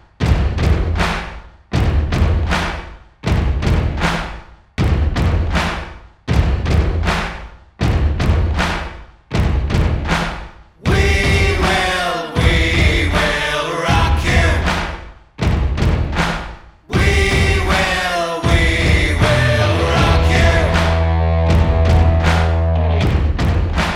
Minus Lead Guitar Rock 2:19 Buy £1.50